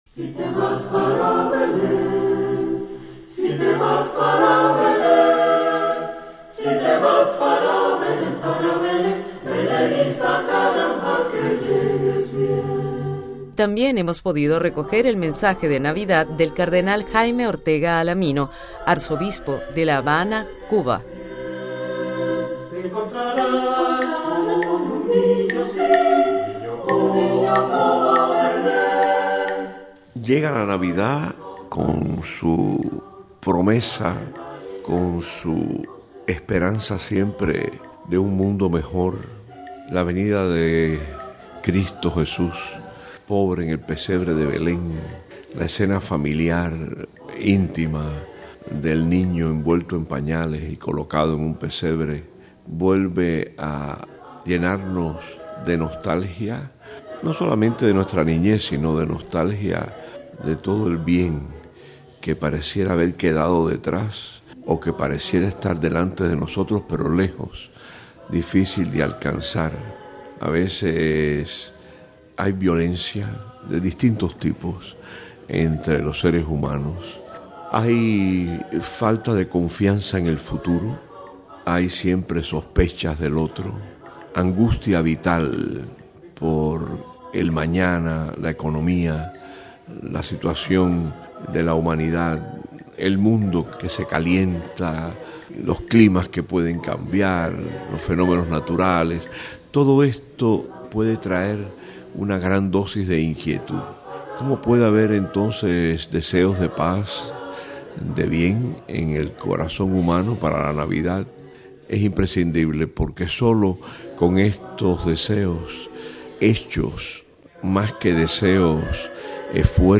Cuando estamos celebrando este período navideño los obispos, los pastores de América Latina desde sus países se dirigen a todos nuestros oyentes con un mensaje de Navidad.